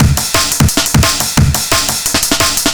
tc_amen2.wav